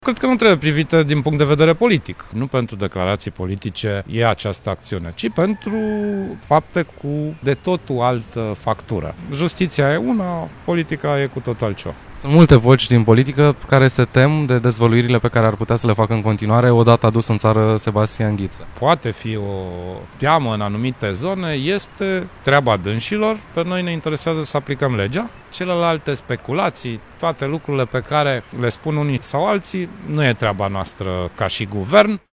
A fost o operațiune complexă care s-a dovedit de succes,  a spus șeful Guvernului pentru TVR.